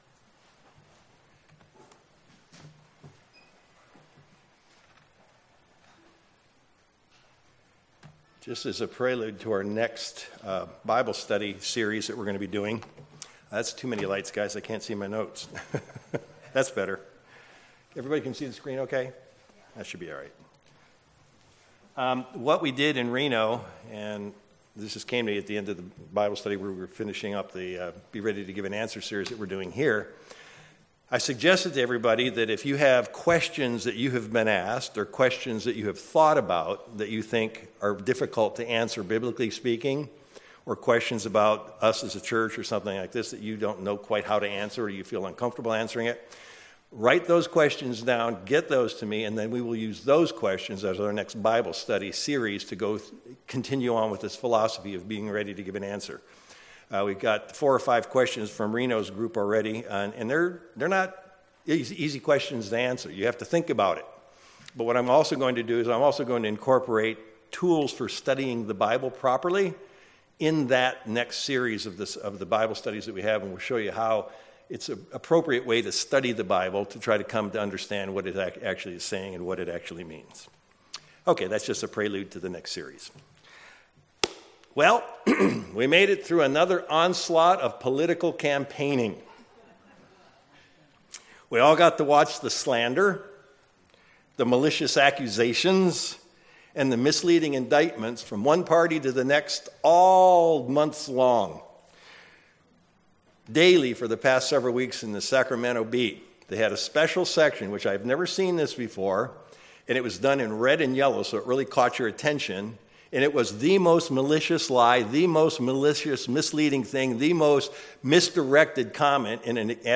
Given in Sacramento, CA
View on YouTube UCG Sermon Studying the bible?